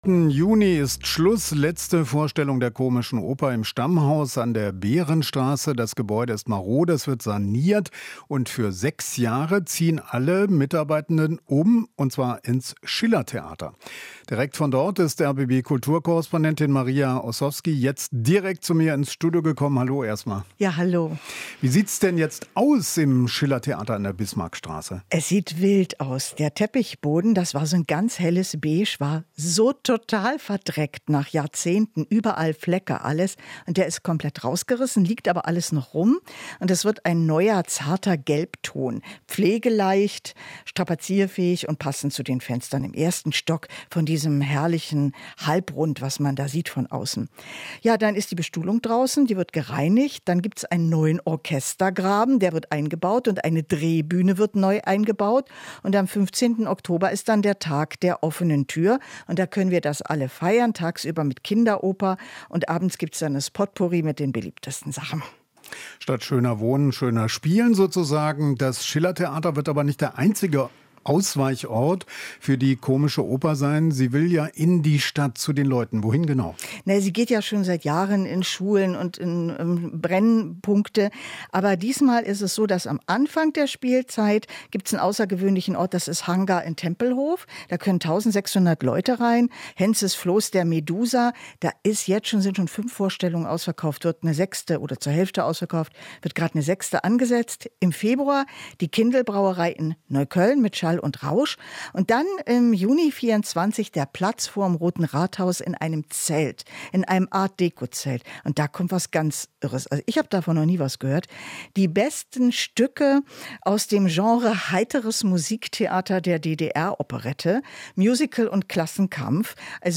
Inforadio Nachrichten, 03.06.2023, 19:00 Uhr - 03.06.2023